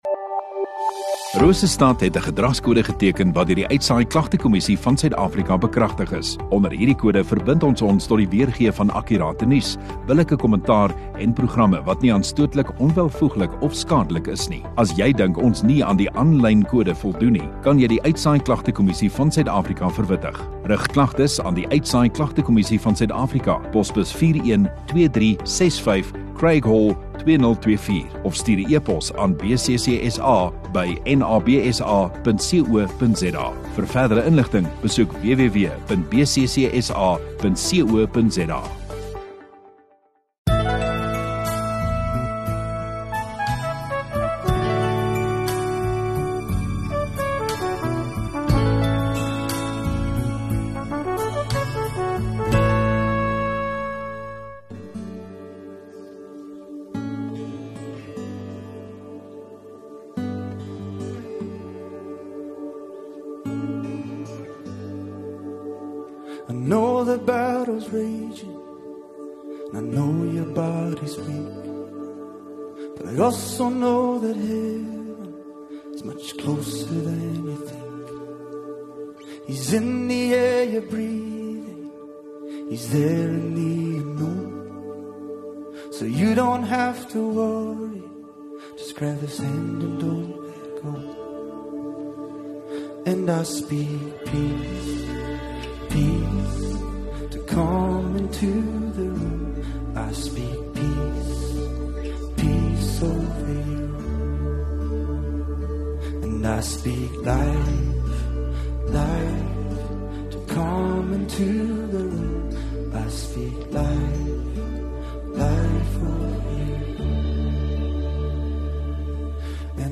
27 Oct Sondagaand Erediens